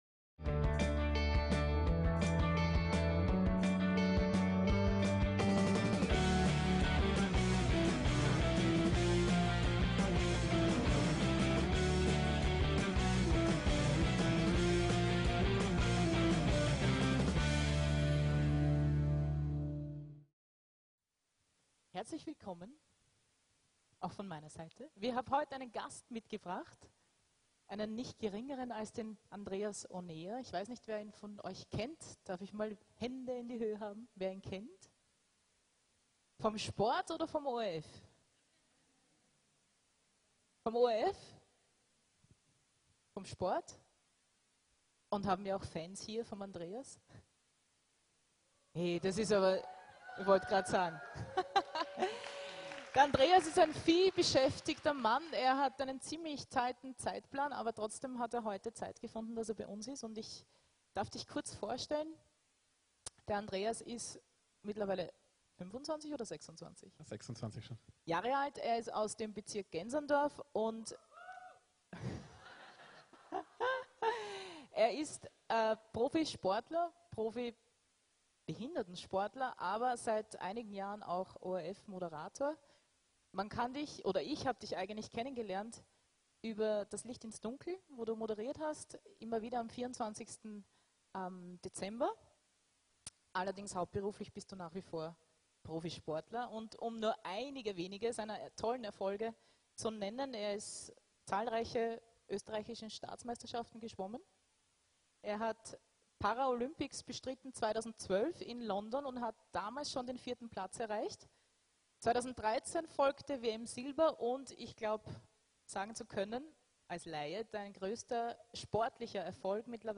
Gottesdienste